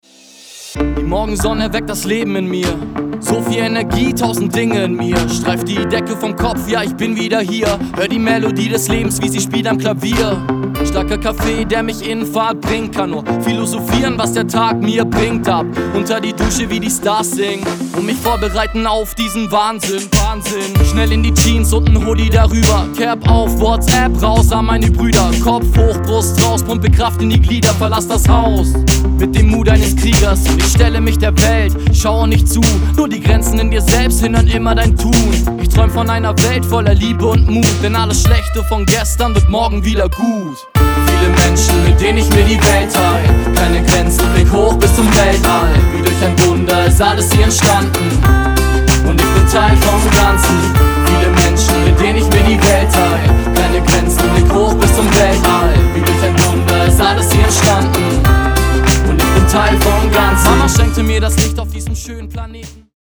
Hip Hop mit positivem Vibe und starken Texten